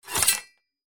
Metal Sound 3